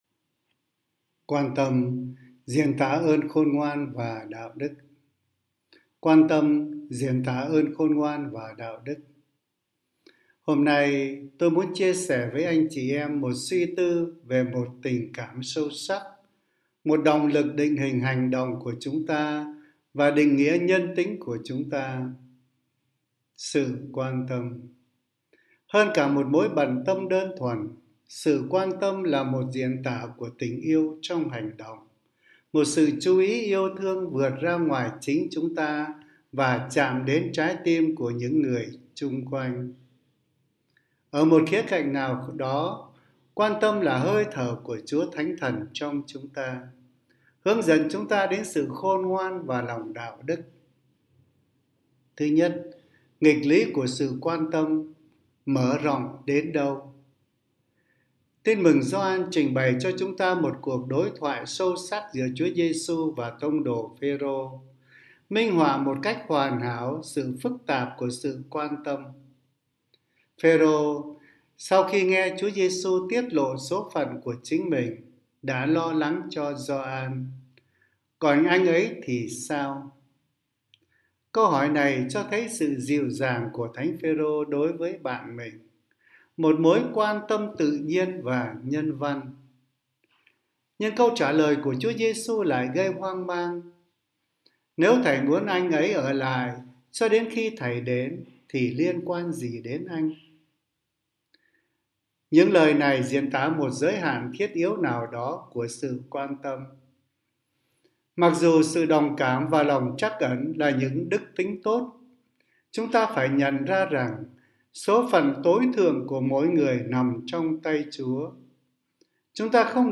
Suy niệm hằng ngày